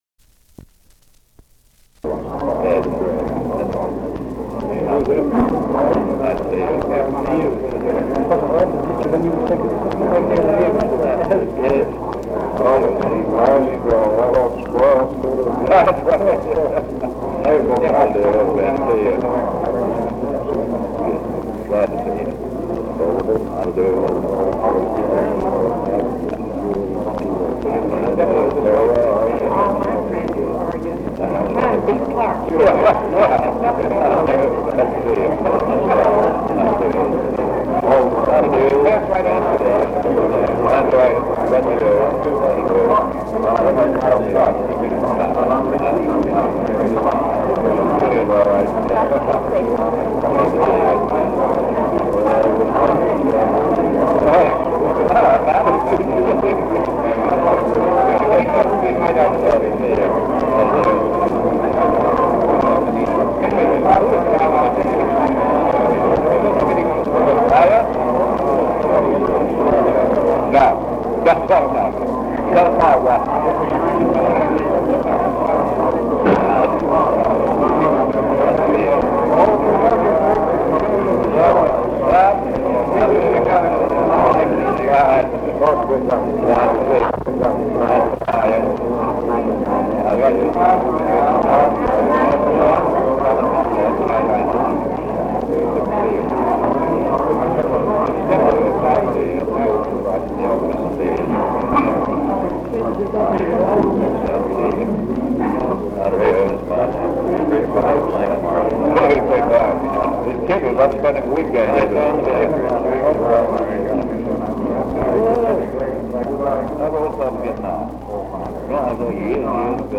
Office Conversation
Secret White House Tapes | Franklin D. Roosevelt Presidency